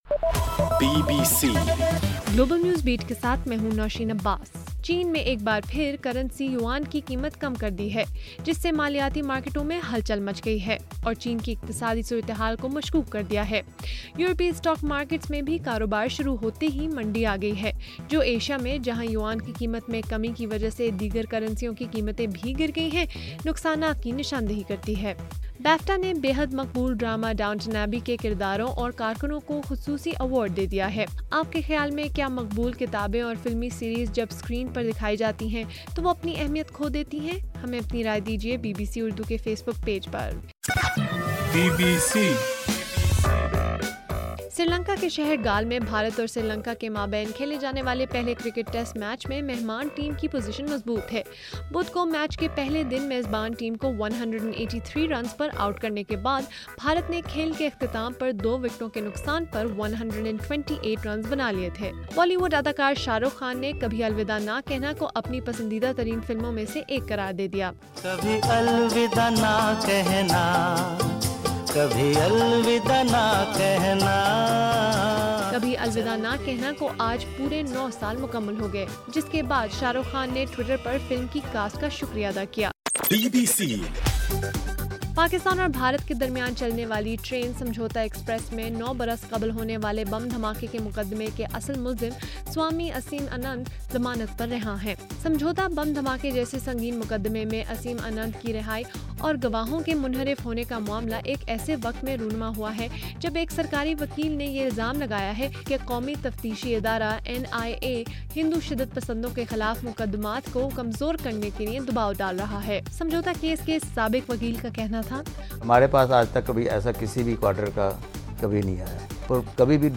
اگست 13: صبح 1 بجے کا گلوبل نیوز بیٹ بُلیٹن